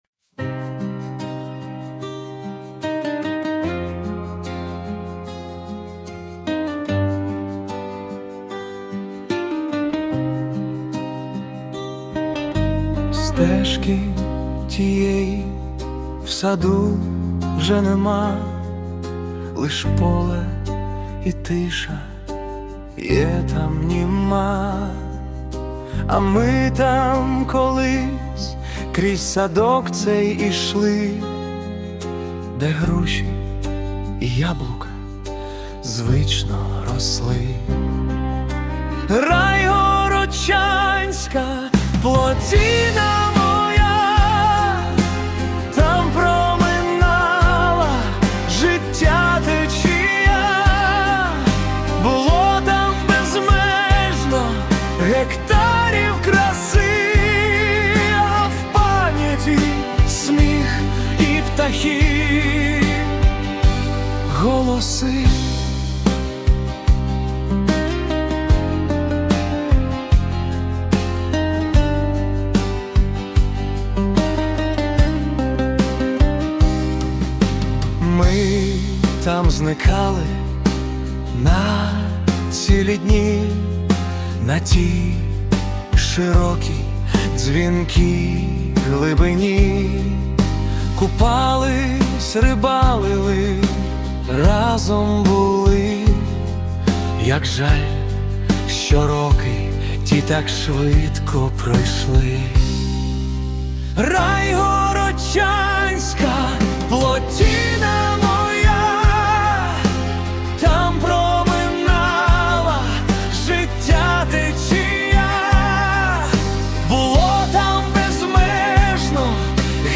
🎵 Жанр: Балада-спогад